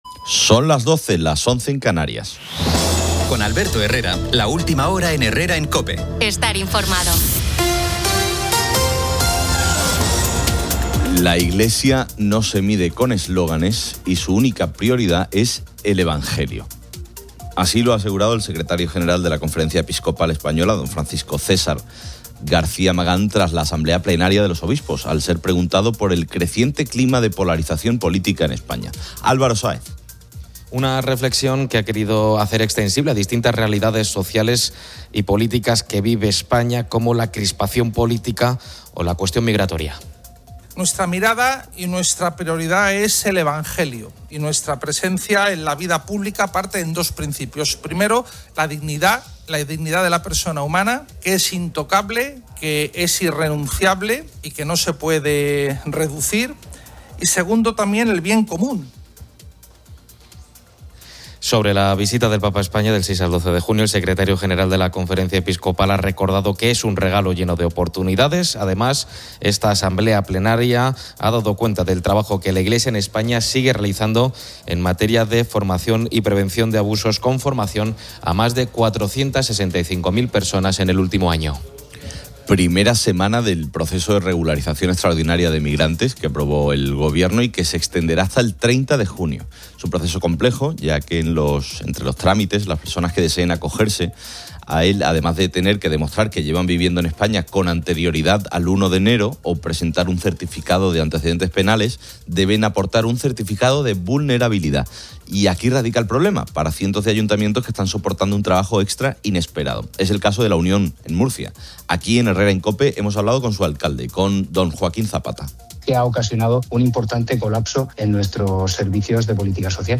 El programa entrevista al legendario músico Paquito D'Rivera, quien comparte reflexiones sobre su carrera, la formación musical y sus recuerdos de Madrid, Cuba y Estados Unidos, anunciando su próxima actuación.